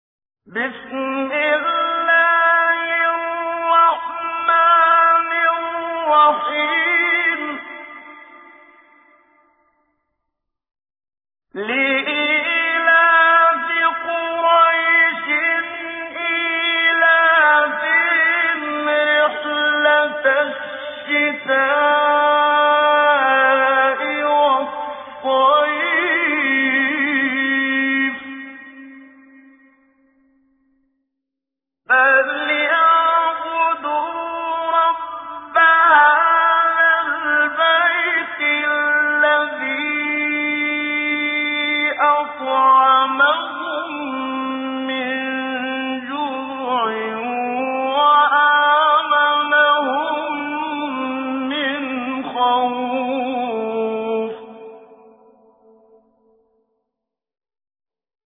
تجويد
سورة قريش الخطیب: المقريء الشيخ محمد صديق المنشاوي المدة الزمنية: 00:00:00